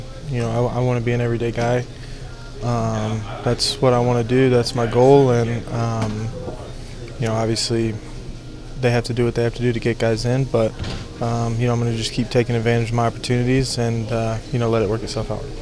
6. Cardinals LF Alec Burleson on hitting a HR in a rare start